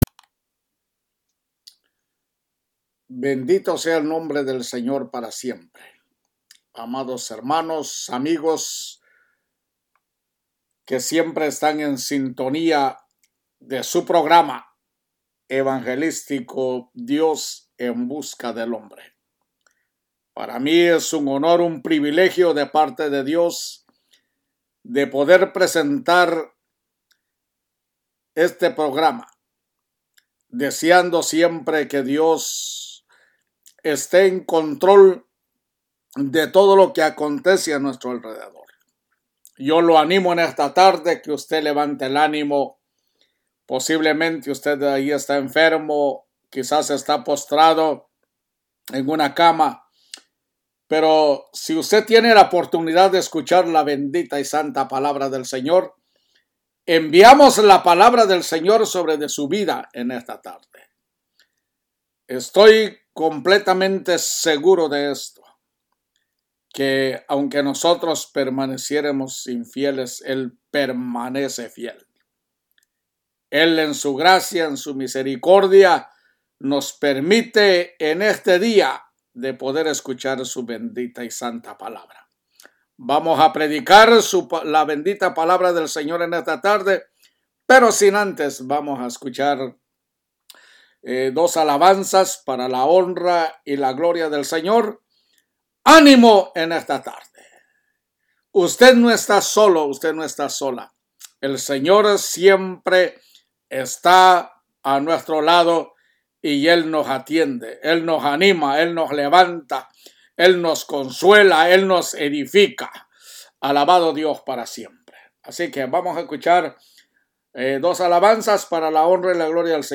EL HOMBRE INOCENTE O CULPABLE PREDICA #3 PARTE #1